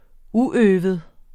Udtale [ ˈuˌøːvəð ]